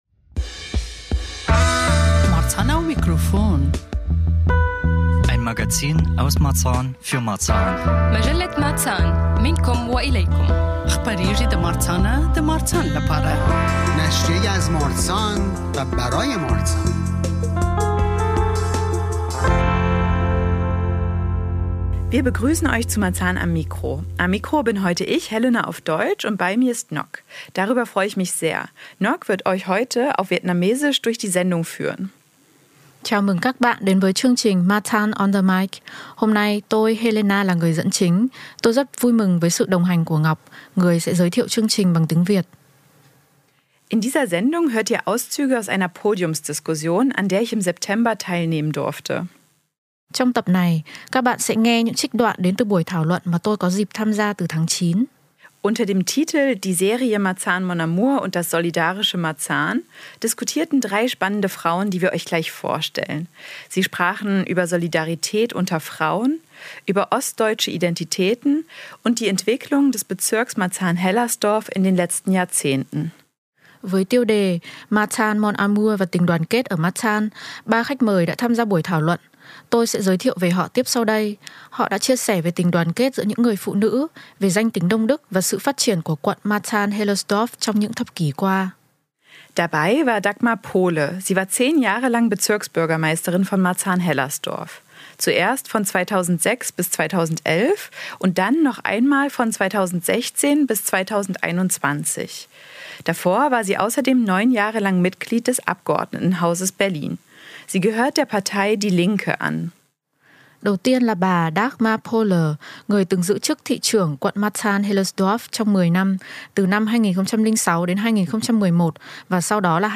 Podiumsdiskussion über das solidarische Marzahn ~ Marzahn am Mikro Podcast
Beschreibung vor 4 Monaten (deutsch/vietnamesisch) Unter dem Titel „Die Serie »Marzahn-Mon Amour« und das solidarische Marzahn“ fand im September 2025 eine Podiumsdiskussion in der Berliner Landeszentrale für politische Bildung statt.